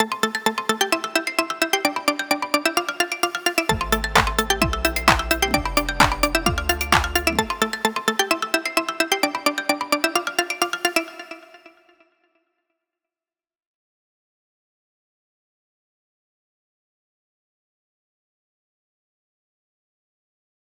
ניסיתי טיפה להוסיף לזה ויריאציות, אז זה באמצע עבודה, אל תתיחסו לפסנתר רק מה דעתכם על הסיידצן, זה מוגזם?
טרק לייצוא חדש גדול עם סיידצן.wav